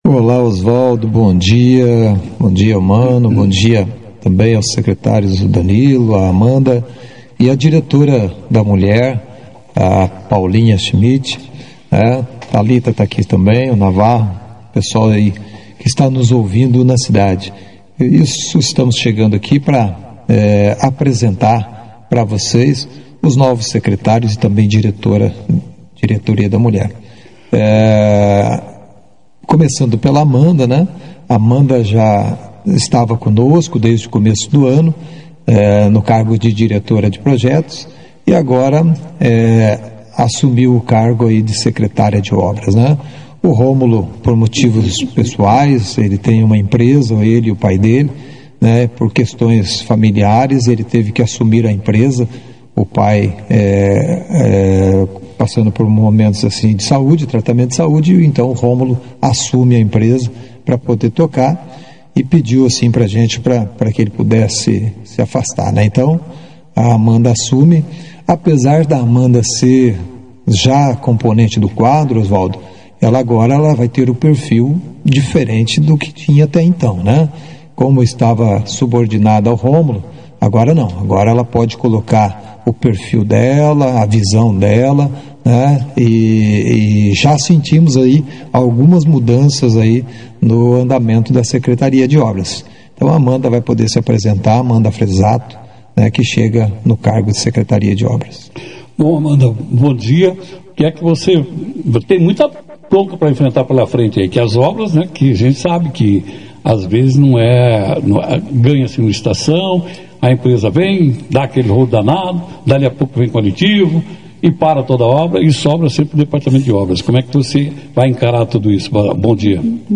Durante a entrevista, eles estiveram acompanhados por parte da nova equipe de secretários, que assumiu suas funções na última semana.